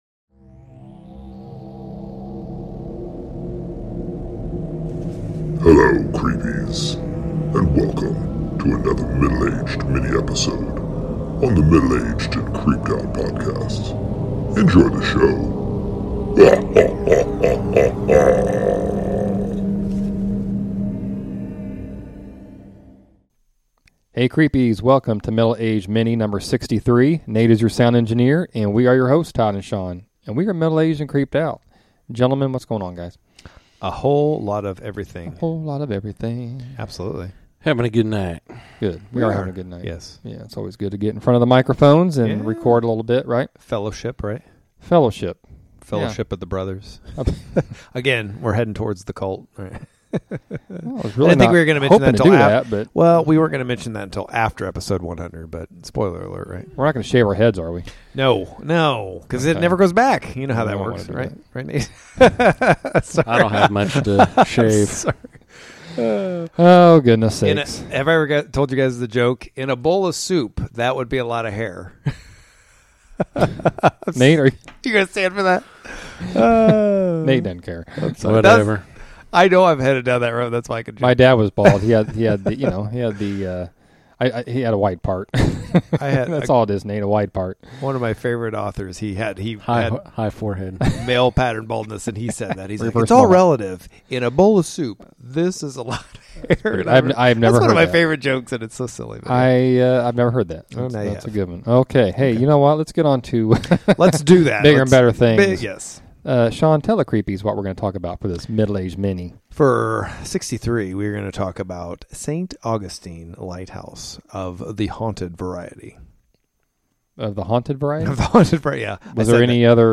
The guys travel south to the oldest and most haunted American city, as the discussion illuminates...St. Augustine Lighthouse!!!